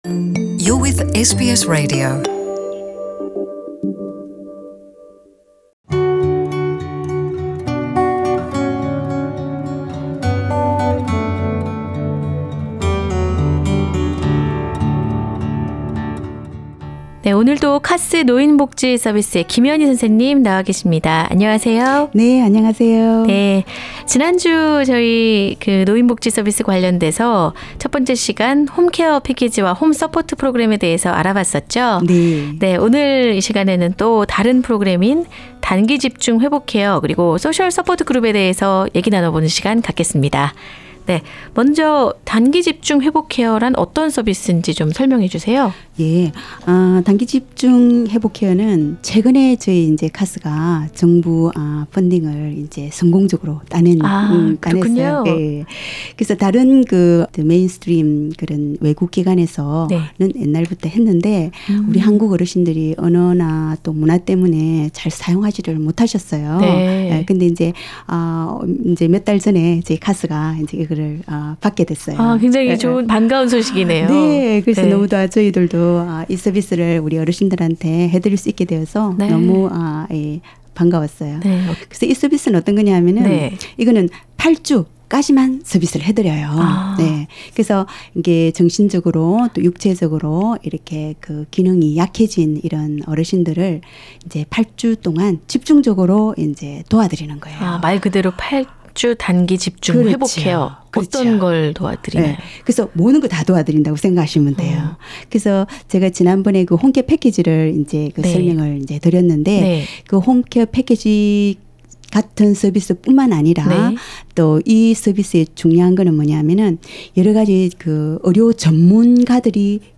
There are Korean Aged Day activity groups running in Ashfield, which goes on a weekly basis. [The full interview is available on the podcast above] Share